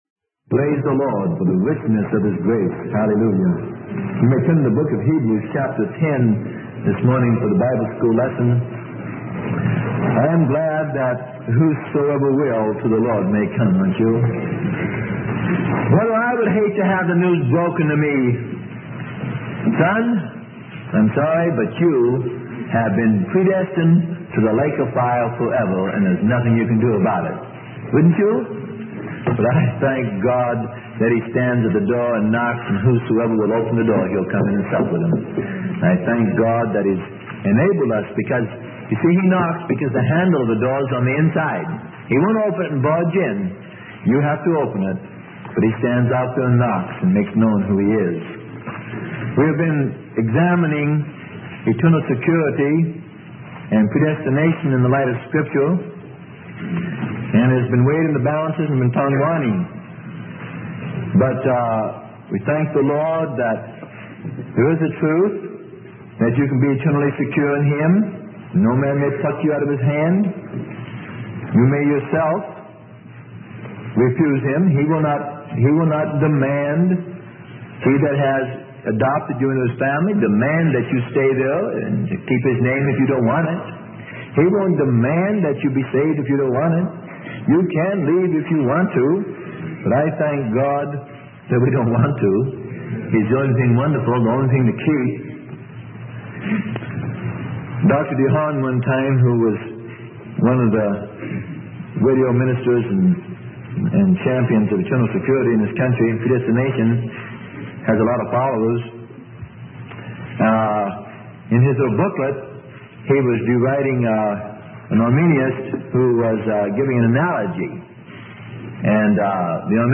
Sermon: Calvinism - Part 08 - Unpardonable Sin - Freely Given Online Library